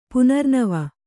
♪ punarnava